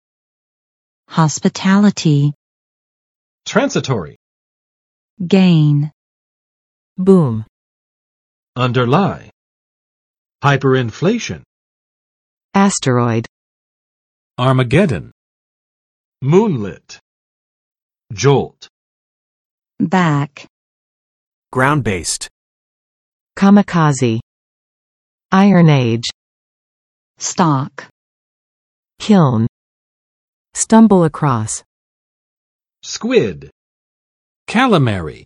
[͵hɑspɪˋtælətɪ] n. 招待性(行业: 如旅馆、饭店等）
[ˋtrænsə͵torɪ] adj. 短暂的